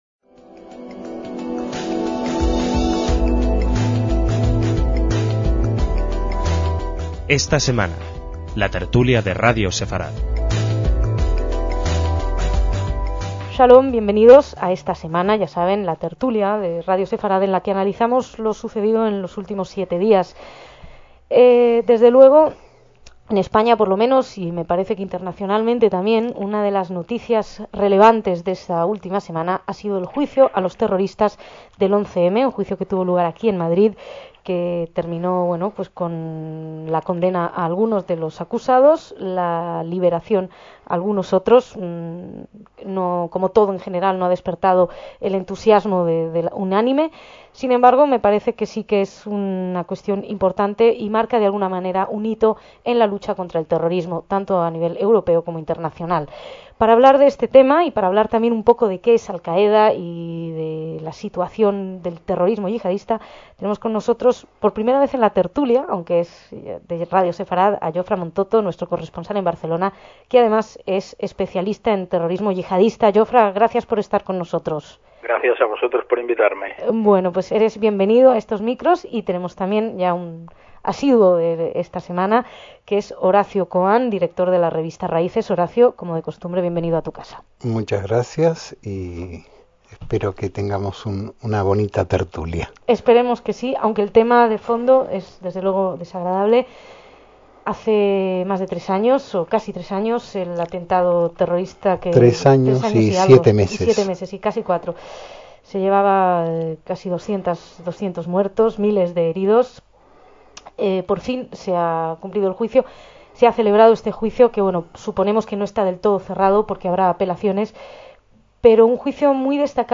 DECÍAMOS AYER (3/11/2007) - Los invitados a esta tertulia debaten en esta ocasión en torno al juicio a los terroristas de 11M madrileño.